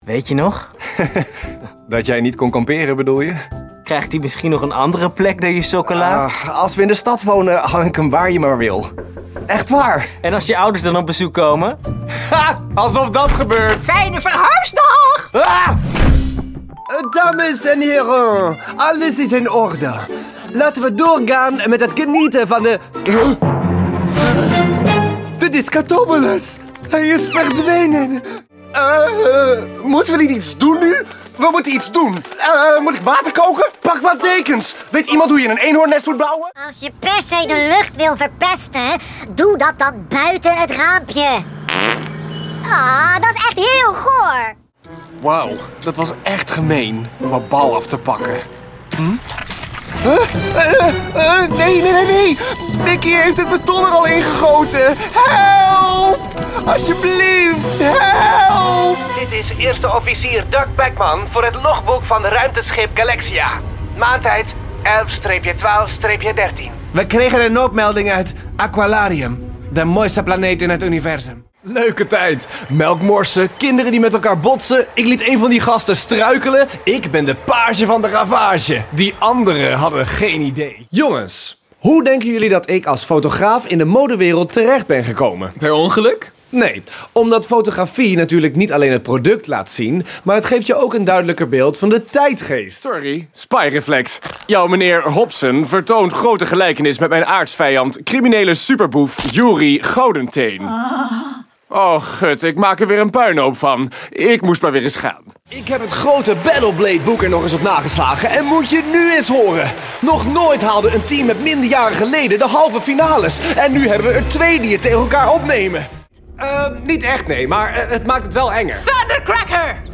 Commercieel, Veelzijdig, Vertrouwd, Vriendelijk, Warm
Think of a fresh, mature voice with that typical millennial sound: clear, accessible and fresh.